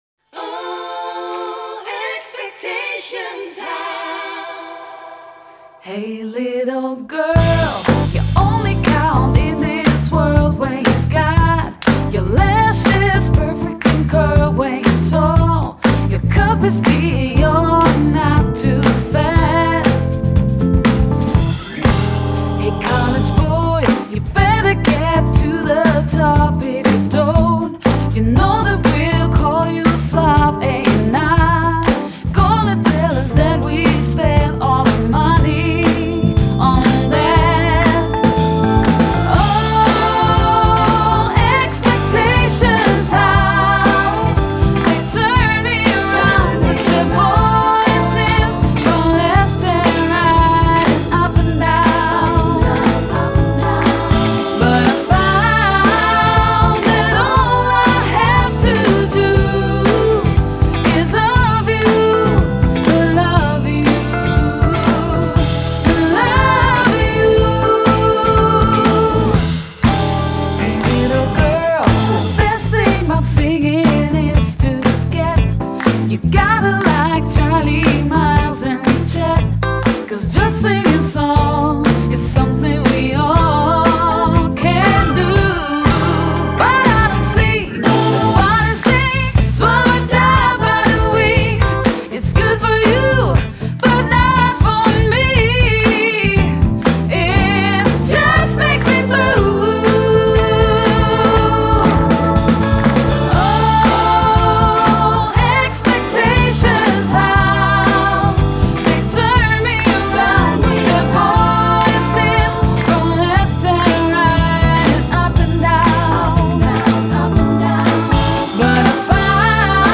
Genre: Pop, Folk Pop, Jazzy Pop